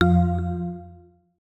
UIClick_Clean Tonal Button 03.wav